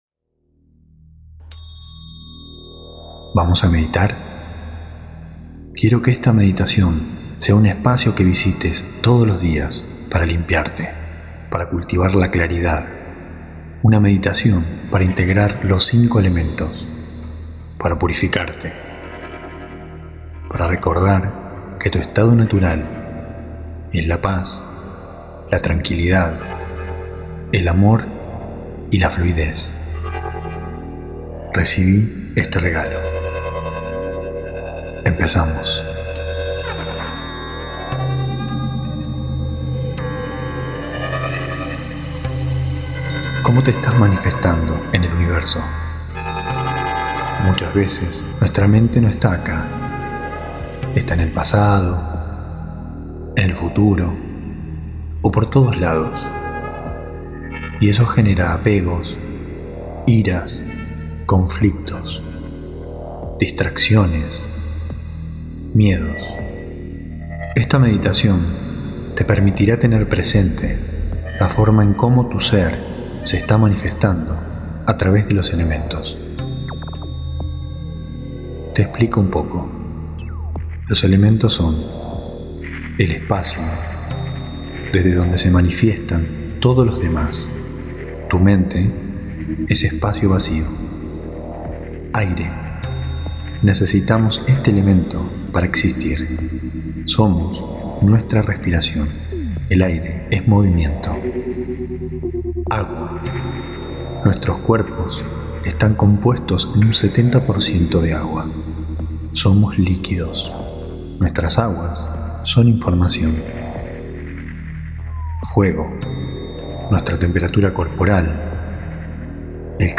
Meditación Elemental (usar )
[Altamente recomendable escuchar con auriculares] Hosted on Acast.